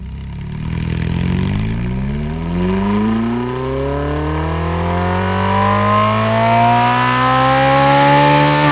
rr3-assets/files/.depot/audio/Vehicles/v8_07/v8_07_Accel.wav
v8_07_Accel.wav